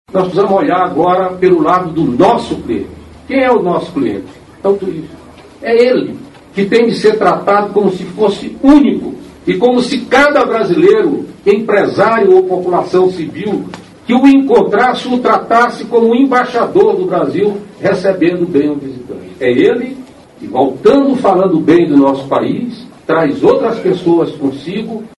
aqui para ouvir declaração do ministro Gastão Vieira sobre a importância do bom atendimento a visitantes.